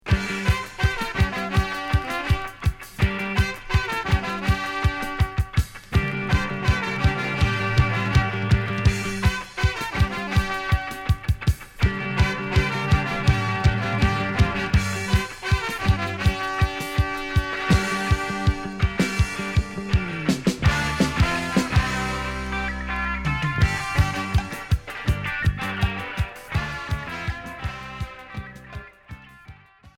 Reggae Rock latino